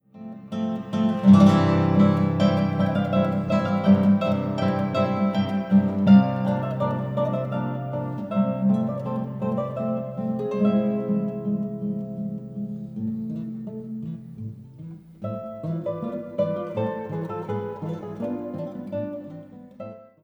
ギター
録音場所：滋賀県、ガリバーホール
録音方式：ステレオ（デジタル／セッション）
原曲のピアノとはまた一味違うギター独自の色彩感豊かな表現となっている。
息の合ったアンサンブルと多彩な音色が光り、穏やかな空気感が心地よい。